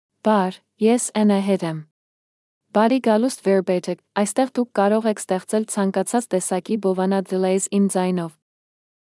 AnahitFemale Armenian AI voice
Anahit is a female AI voice for Armenian (Armenia).
Voice sample
Listen to Anahit's female Armenian voice.
Female